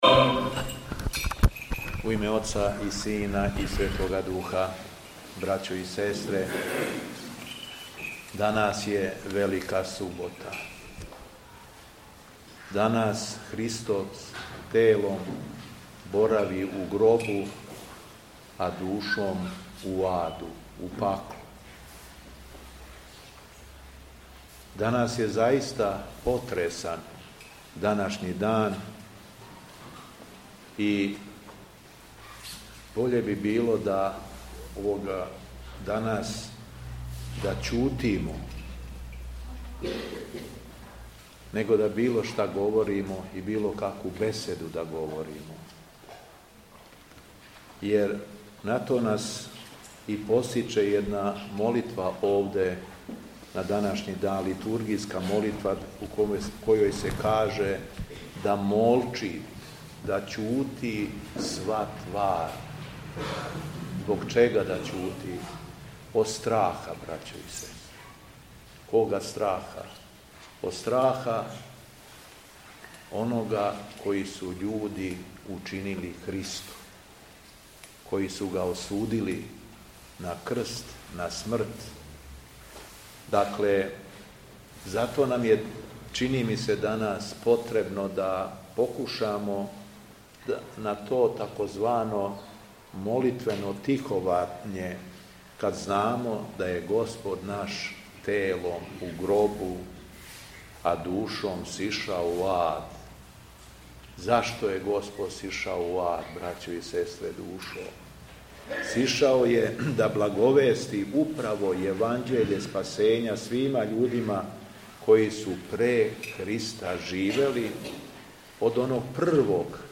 ВЕЛИКА СУБОТА У ХРАМУ СВЕТОГ ПАНТЕЛЕЈМОНА У СТАНОВУ
Беседа Његовог Високопреосвештенства Митрополита шумадијског г. Јована
У Свету и Велику суботу, 11. априла 2026. године, када се сећамо погребења и силаска у ад Господа нашег Исуса Христа, Његово Високопреосвештенство Архиепископ крагујевачки и Митрополит шумадијски Господин Јован служио је Свету Литургију у храму Светог великомученика Пантелејмона у крагујевачком насељу Станово.